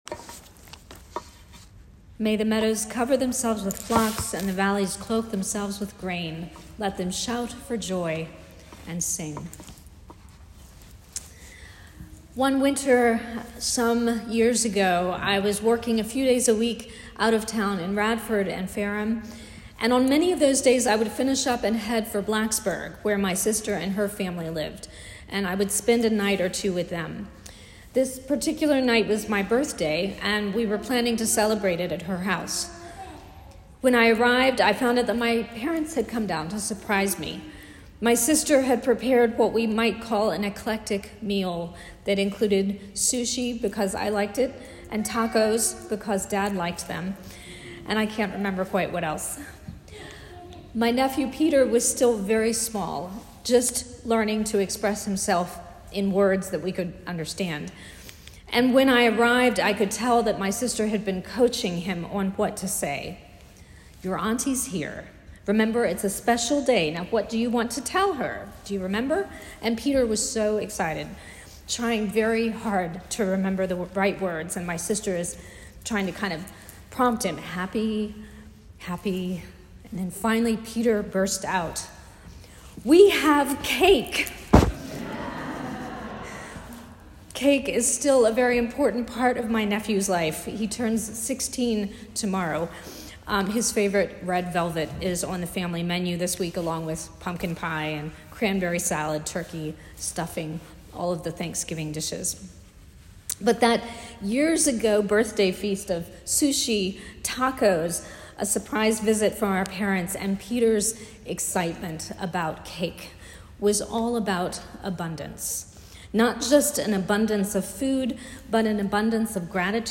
Thanksgiving-sermon.m4a